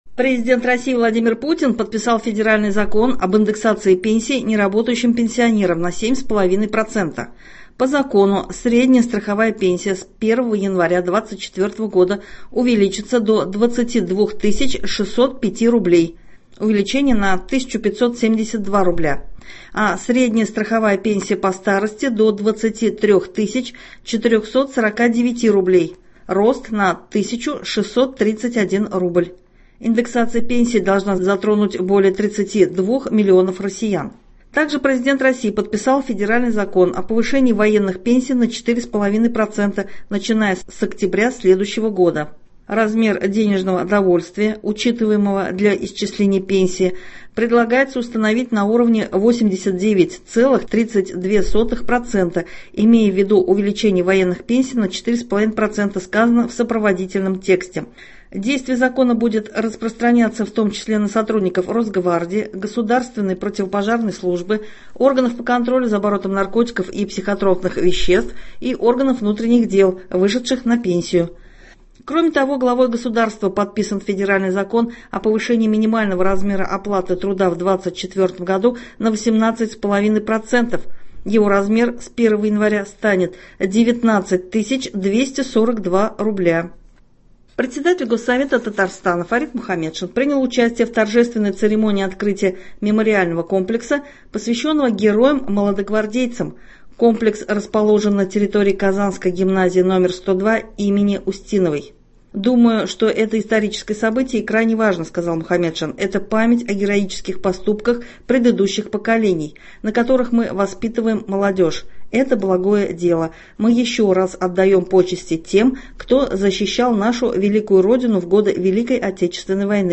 Новости (28.11.23)